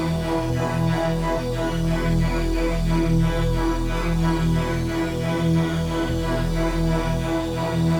Index of /musicradar/dystopian-drone-samples/Tempo Loops/90bpm
DD_TempoDroneD_90-E.wav